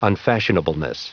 Prononciation du mot unfashionableness en anglais (fichier audio)
Prononciation du mot : unfashionableness